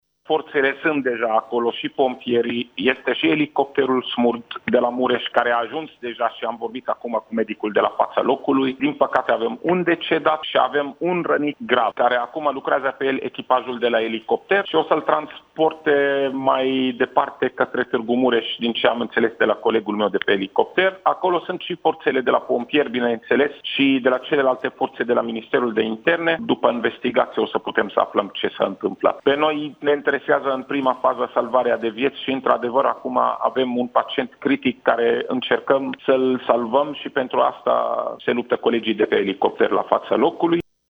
Secretarul de stat dr. Raed Arafat, în direct la Digi 24: